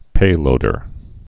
(pālōdər)